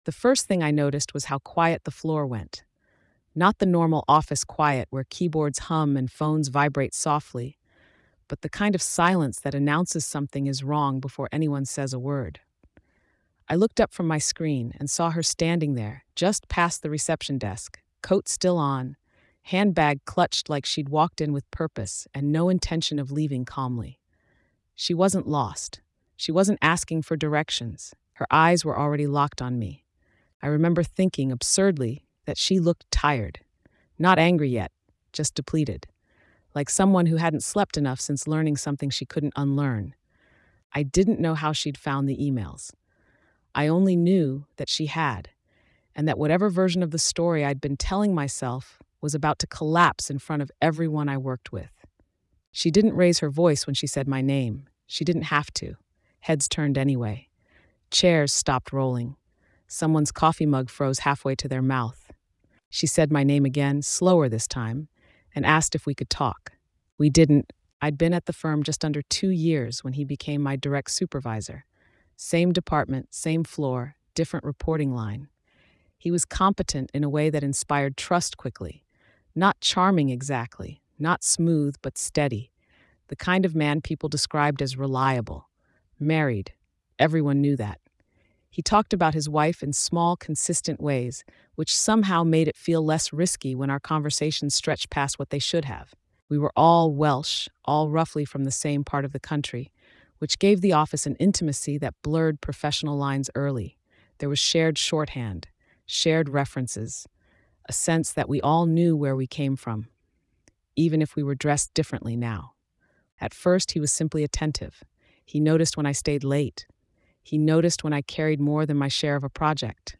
A White Welsh woman recounts the moment her private email relationship with her married supervisor is exposed when his wife confronts her publicly at work. What unfolds is not just personal humiliation, but a revealing look at how workplaces protect authority, reframe accountability, and quietly reposition women as problems to be managed rather than harms to be addressed.